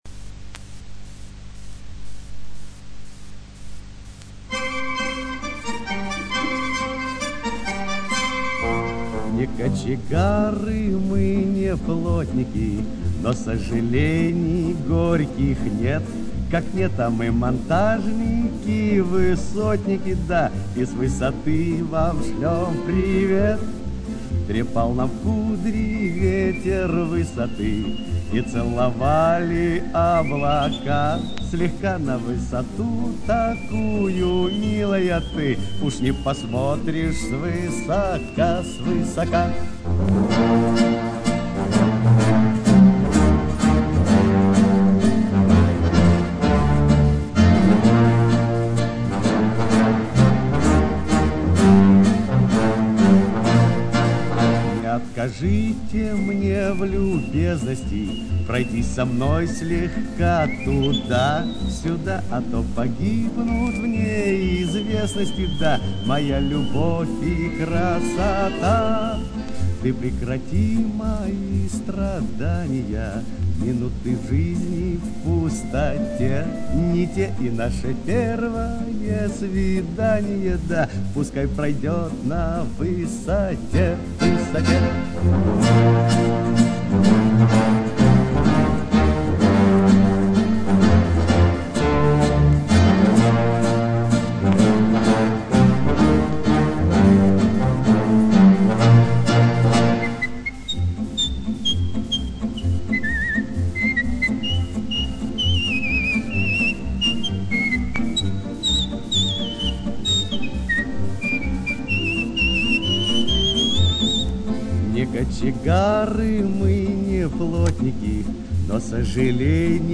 Описание: Песня здесь уже есть, но слишком шипит